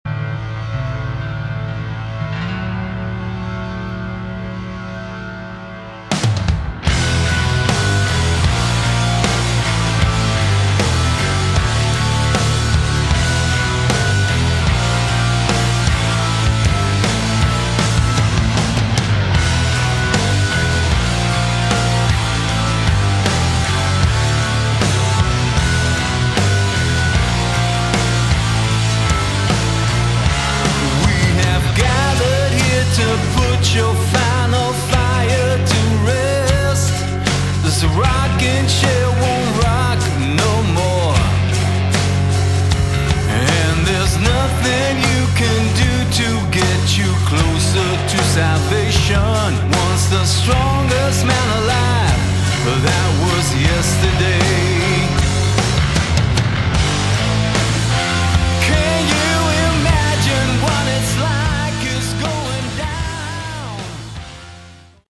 Category: Melodic Hard Rock
lead & backing vocals
lead & rhythm guitar, backing vocals
drums, percussion, backing vocals
organ, keyboards, backing vocals